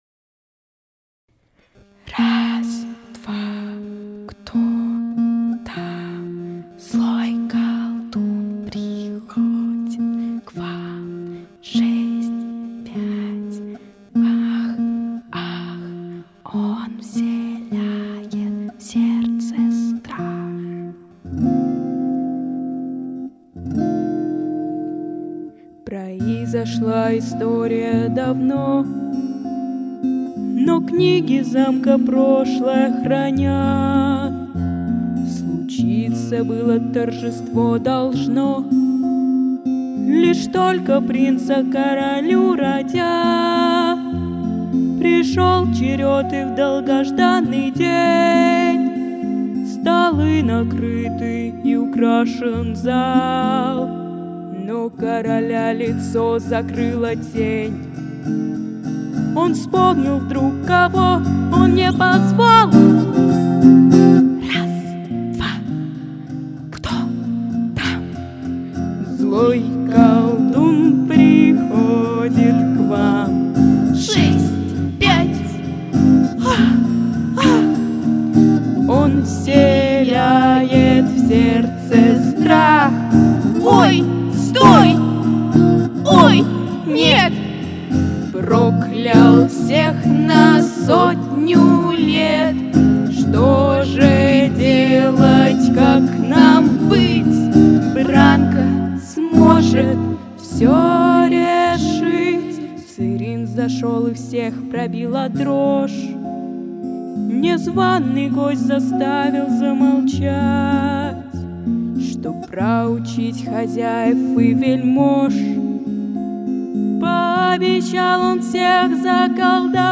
Записано в студии Easy Rider в ноябре–декабре 2025 года